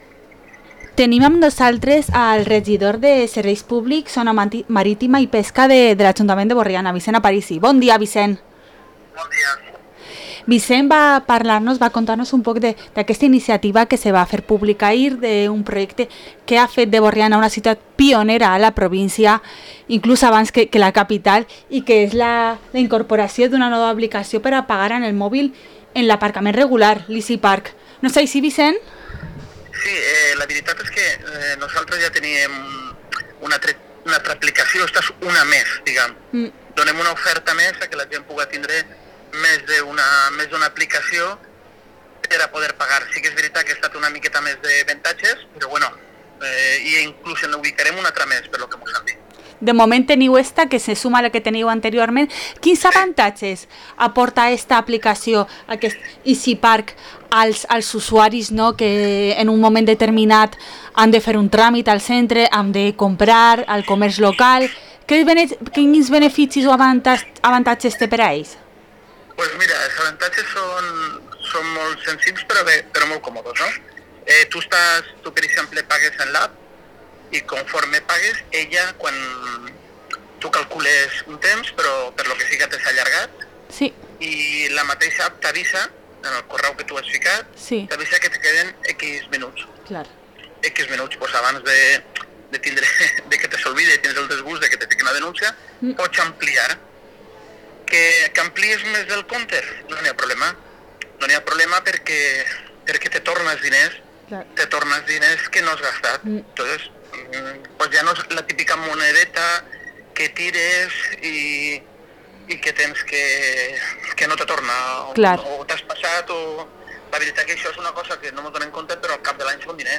Entrevista a Vicent Aparici concejal de Servicios Públicos en el Ayuntamiento de Borriana